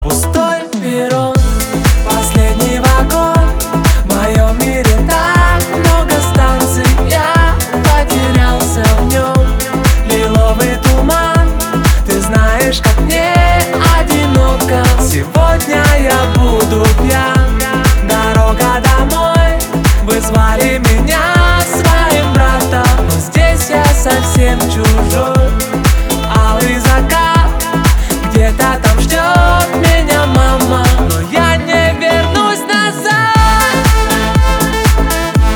• Качество: 320, Stereo
поп
грустные